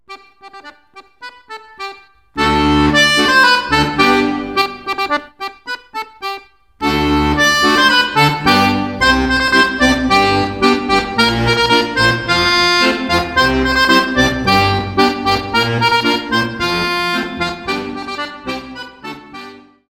harmonikka accordion